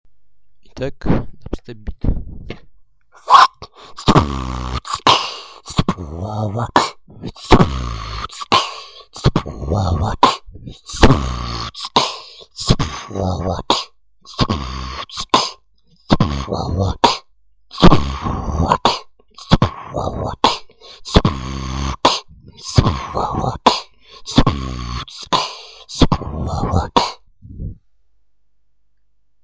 dubstep биты
Секрет прост побольше вибрирующих губок) Можно Brr для мощности добавить.
вот биток: вууть-tt-bww-tt-kch-tt-grb-kch
ЗЗЫ : в пером варенте только 1 раз зделал вуть звинясь)) но суть я передал